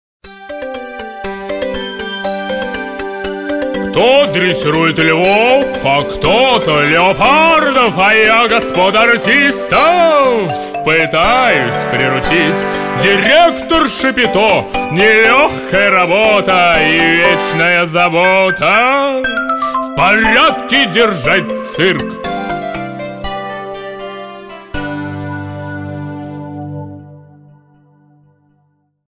песенка из аудио сказки и мультфильфильма